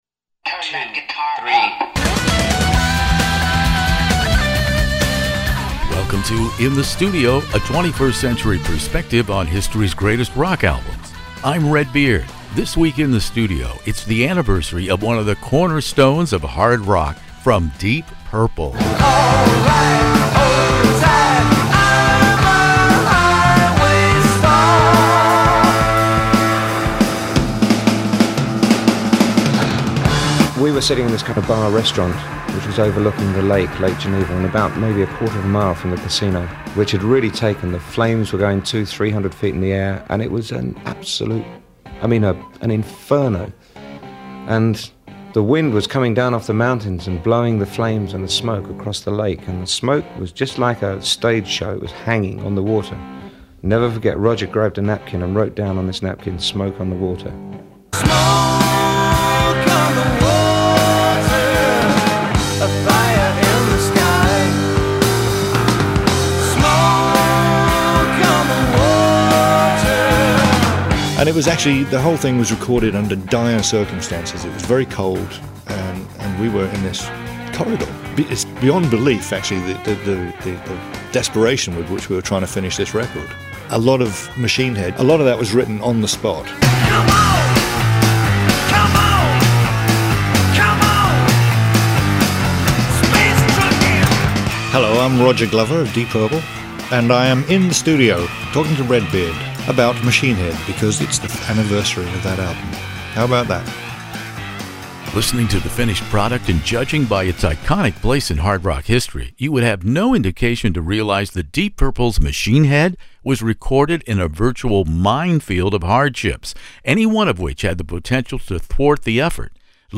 Deep Purple "Machine Head" interview Ian Gillan, Roger Glover In the Studio